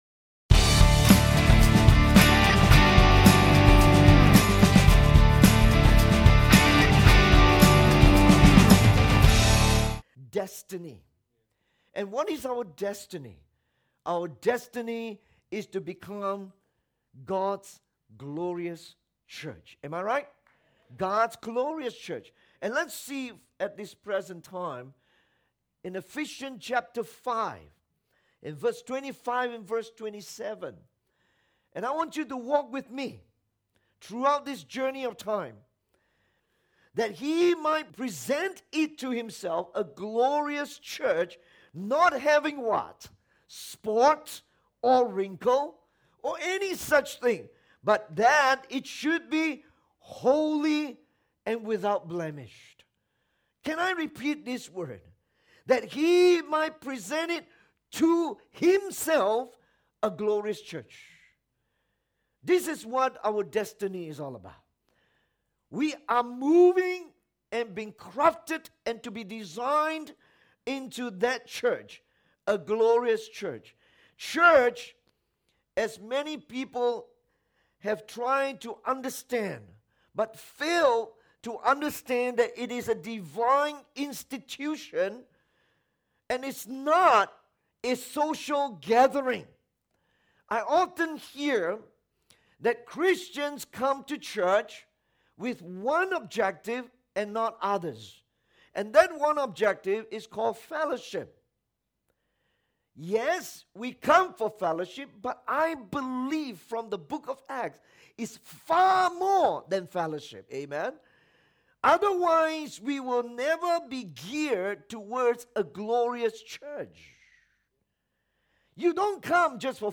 God’s Glorious Church-Anniversary Message